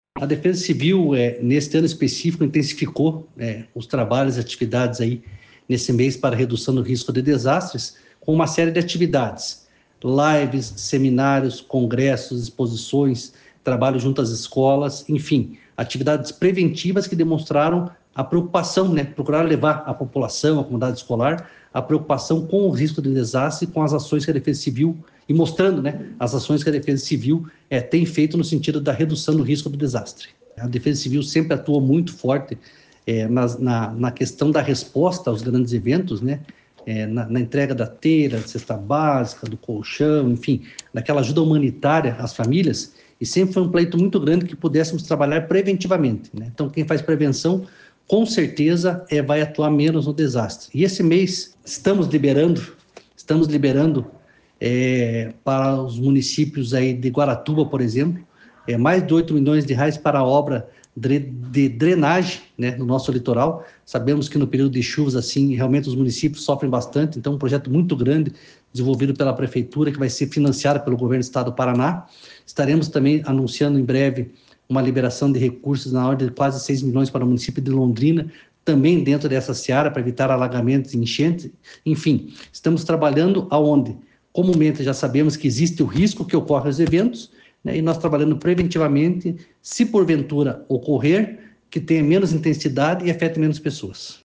Sonora do coordenador da Defesa Civil, coronel Fernando Schünig, sobre Guaratuba ser a 1ª cidade a usar recursos do fundo de calamidades para obras preventivas